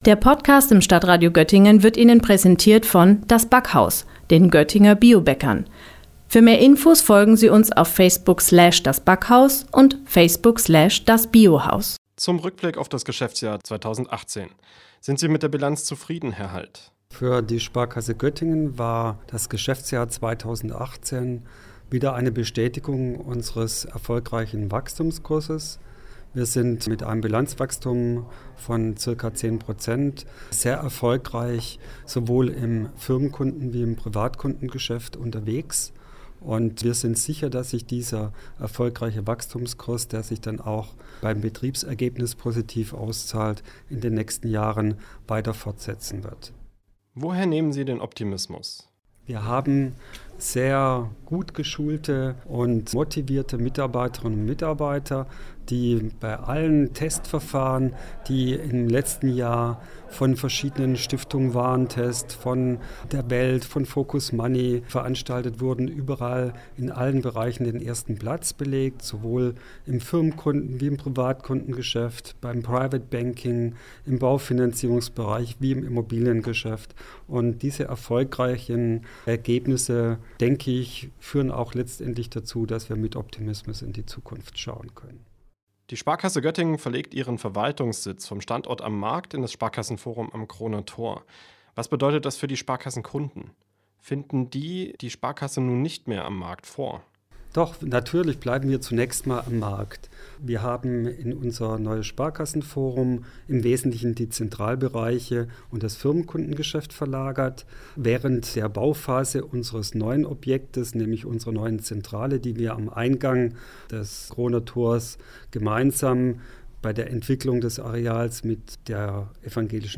Beiträge > Neujahrspressekonferenz Sparkasse Göttingen – Bilanz 2018 - StadtRadio Göttingen